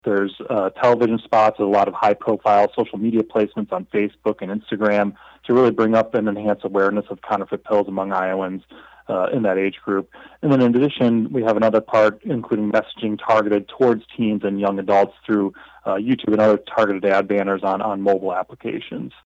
State Medical Director Dr. Robert Kruse says the two-fold messaging is designed to reach children as young as five years old, middle and high schoolers, as well as adults.